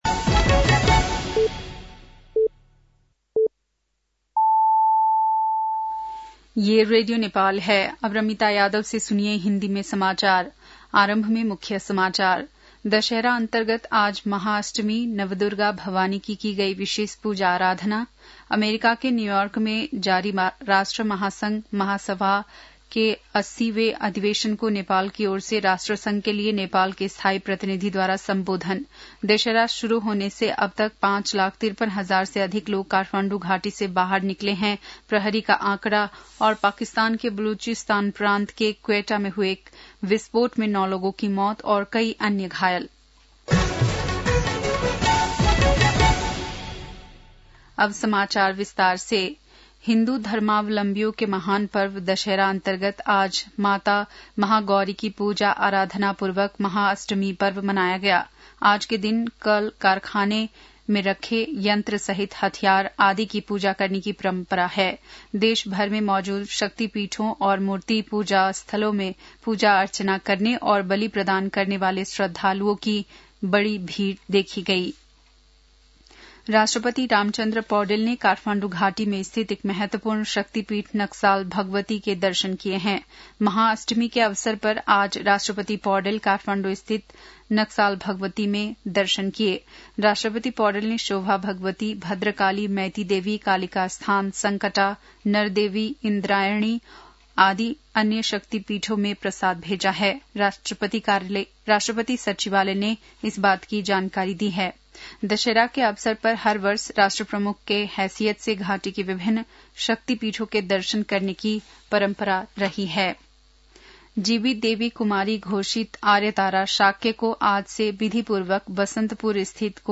बेलुकी १० बजेको हिन्दी समाचार : १४ असोज , २०८२
10-pm-hindi-news-6-14.mp3